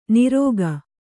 ♪ nirōga